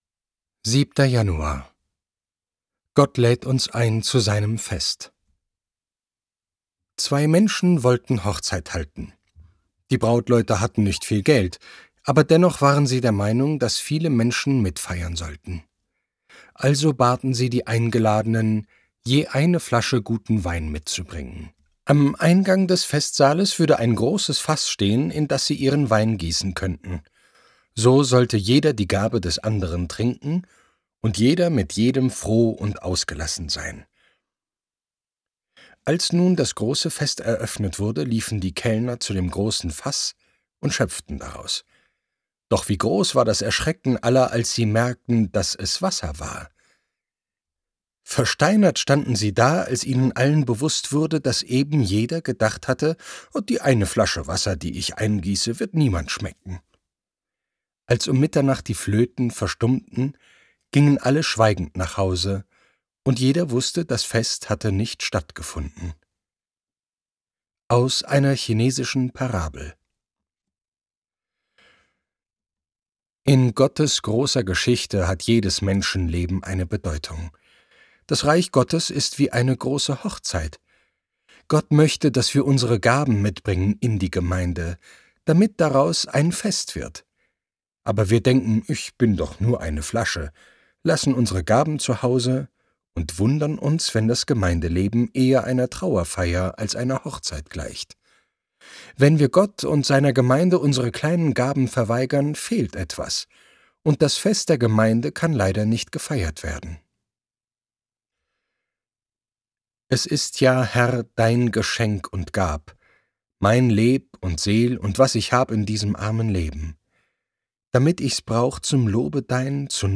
Überlebensgeschichten für jeden Tag - Hörbuch
Ein Hörbuch für 365 Tage